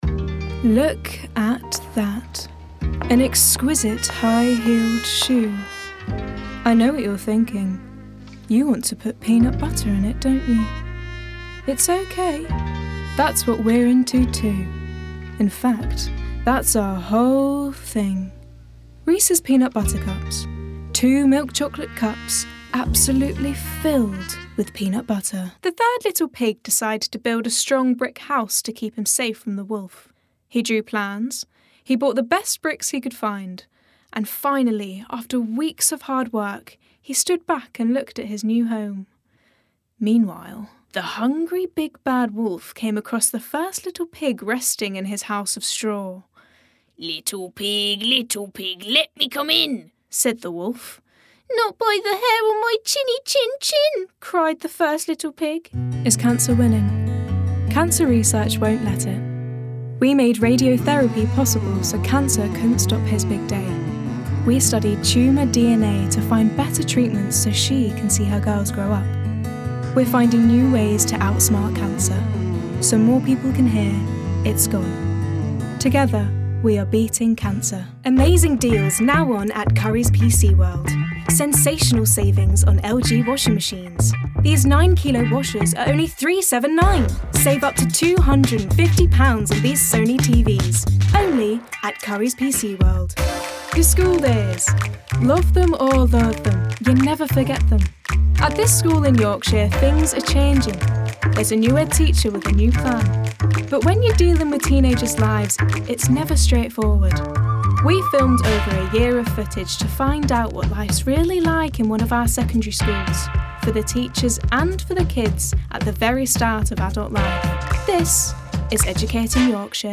Native voice:
Estuary English
Voicereel:
BRITISH ISLES: Heightened RP, Contemporary RP, Northern-Irish, Yorkshire, Liverpool, Essex
GLOBAL: Standard-American, American-Southern States